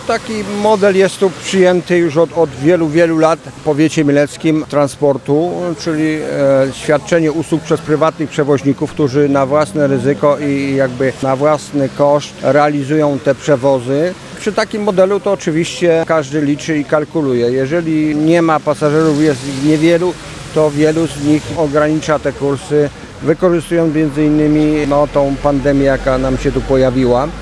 Jak tłumaczyli nam przewoźnicy a także starosta mielecki Stanisław Lonczak, powód takiej sytuacji na chwilę obecną to nierentowność samych przewozów jak i frekwencja samych podróżujących w czasie pandemii.